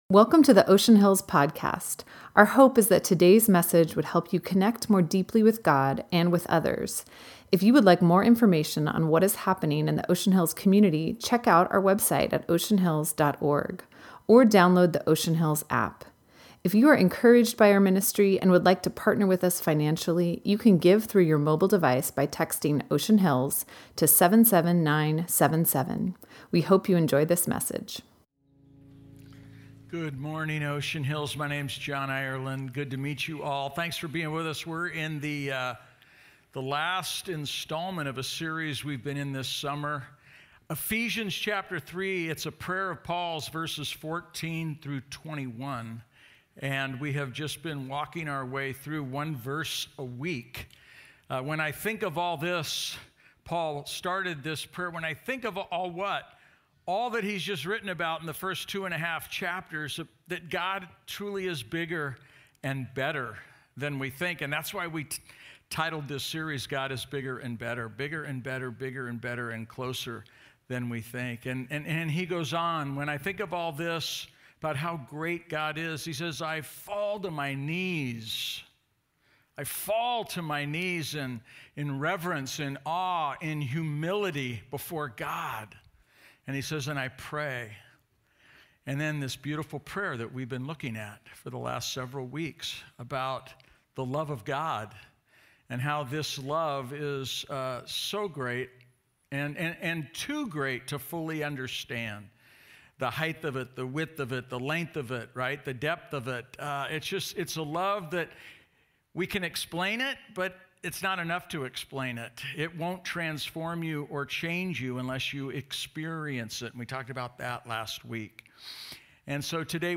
Past sermons at Oceanhills Covenant Church